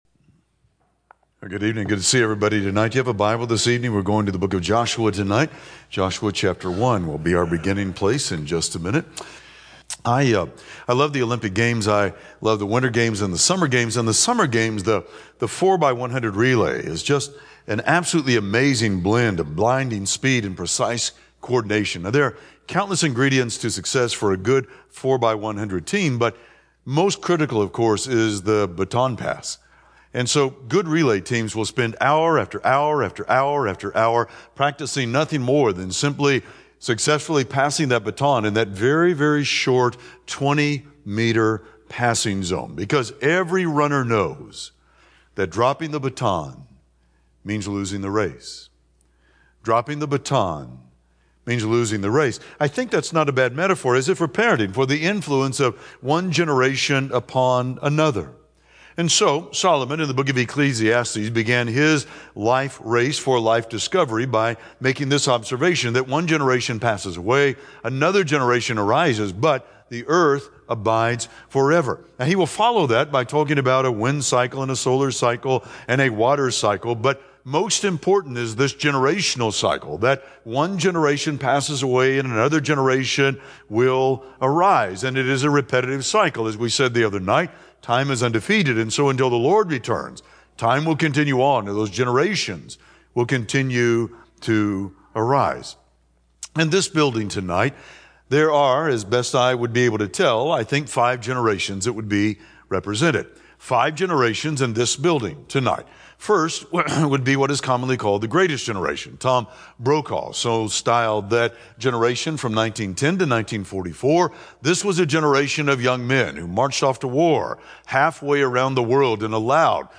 Service: Tues PM Type: Sermon